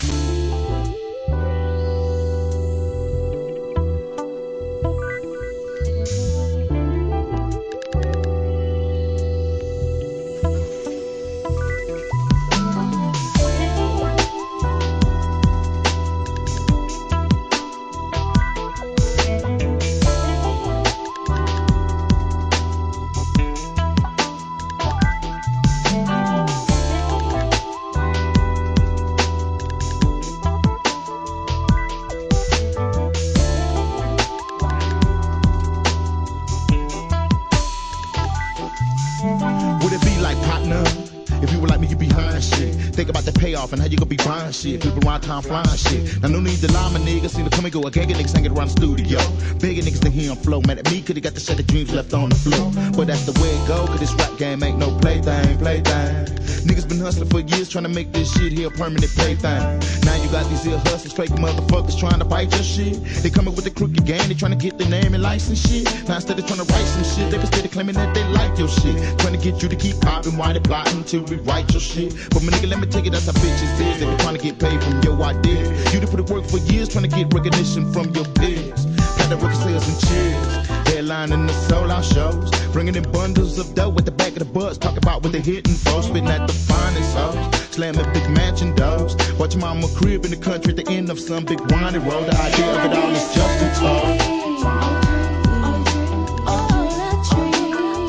1. G-RAP/WEST COAST/SOUTH
1997年、フィメール・ヴォーカルの絡みもNICEなSLOW BOUNCE!!!